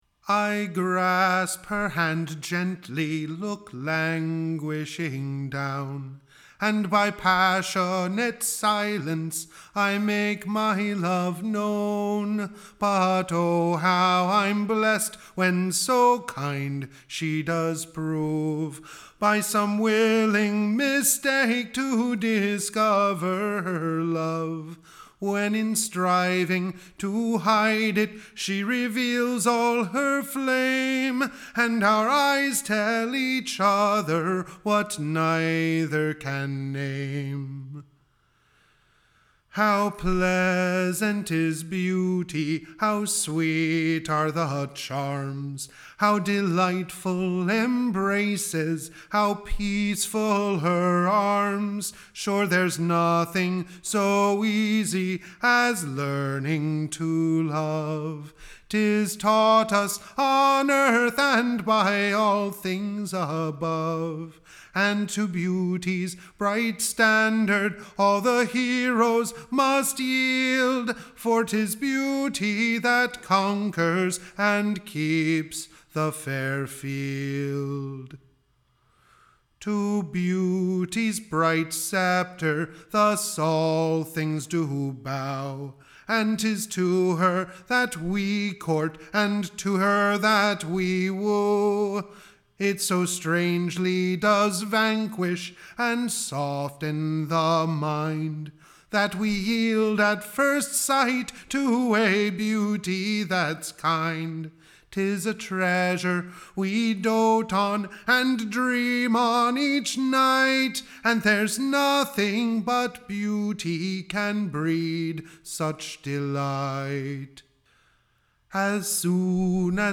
EBBA 22434 - UCSB English Broadside Ballad Archive